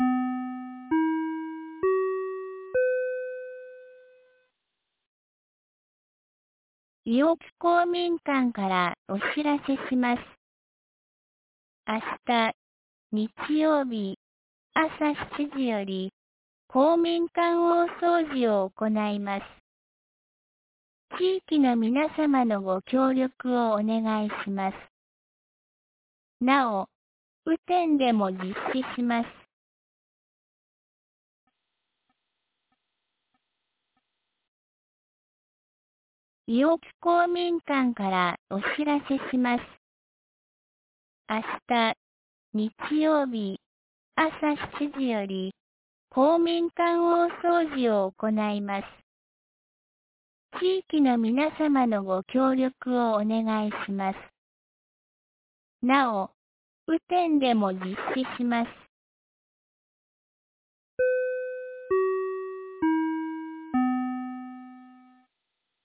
2024年07月06日 17時21分に、安芸市より伊尾木へ放送がありました。
放送音声